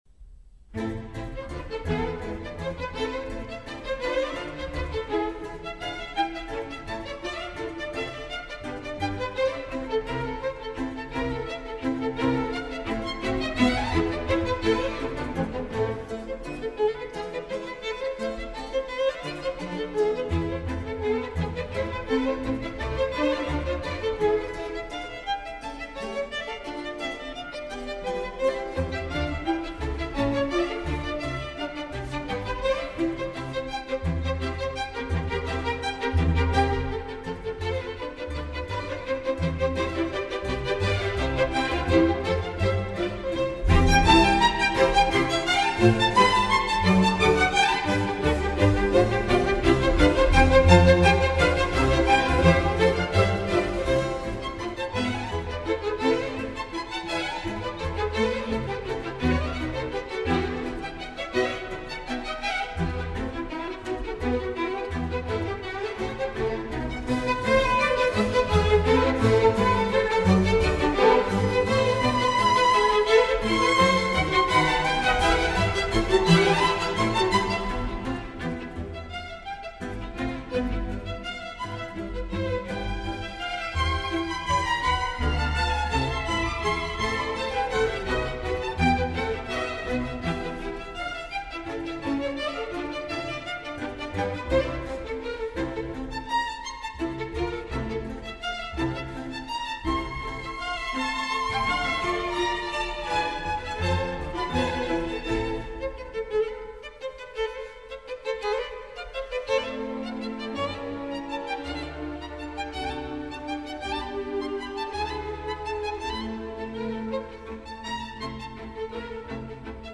D10- 08 Haendel Concerto grosso in A, Fourth mov | Miles Christi